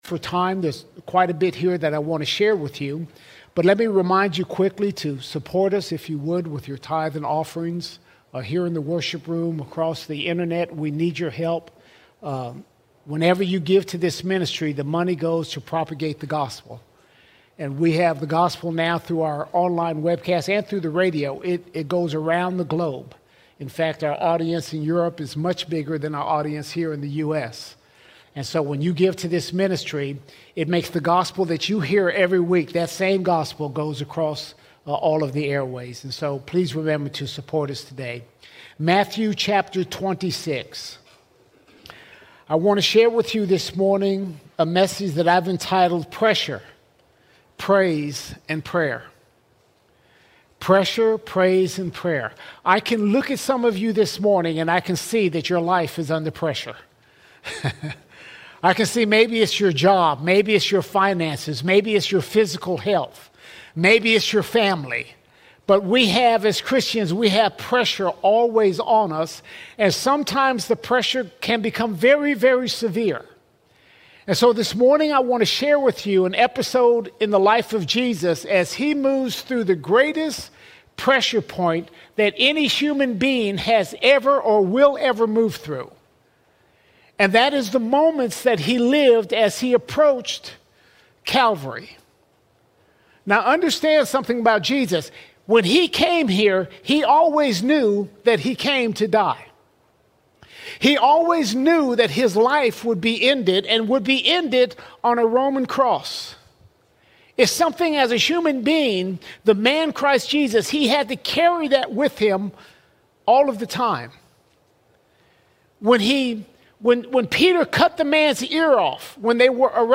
22 September 2025 Series: Sunday Sermons All Sermons Pressure, Praise and Prayer Pressure, Praise and Prayer Life brings pressure from every side, but God has not left us without help.